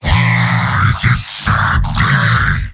Giant Robot lines from MvM. This is an audio clip from the game Team Fortress 2 .
Heavy_mvm_m_jeers05.mp3